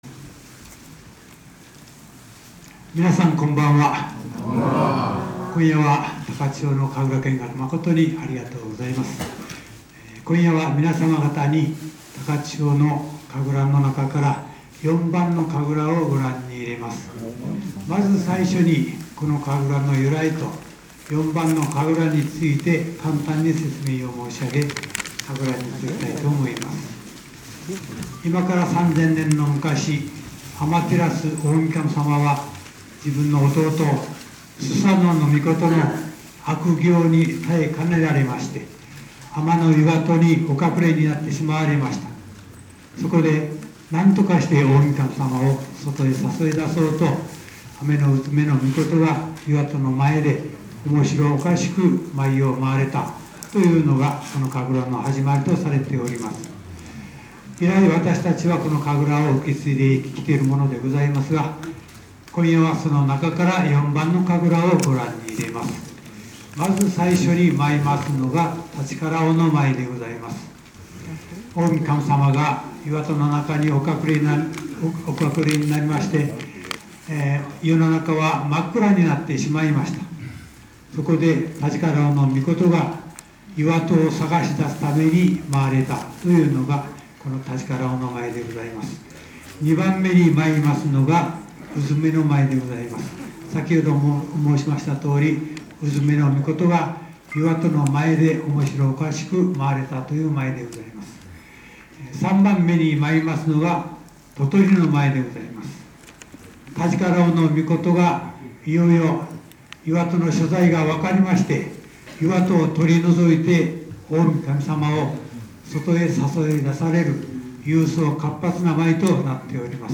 ・　旅先で得た音楽や音をアップしました。
◎　夜神楽口上
ホテルで「山の幸」を中心にした心づくしの夕食をいただき、高千穂神社に夜神楽見学に出かけた。